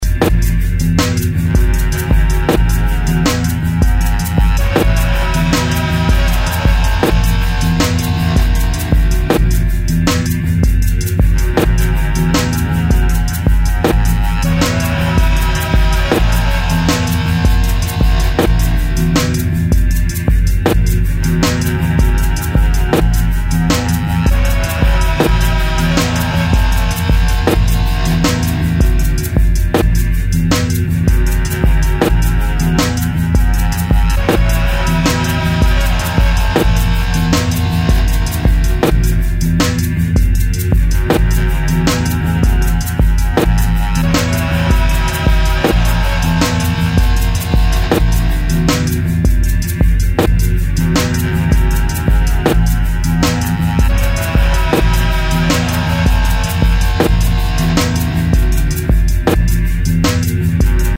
wokalizowany
z unacześnioną elektroniką